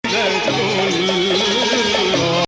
This performance actually uses the Zeffa rhythm typical in weddings.
Maqam Bayati
Performer: Mohamed Kheyri
Bayati 1
Bayati Ah Ya Hilu 23 Bayati.mp3